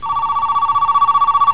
telephone
phone.au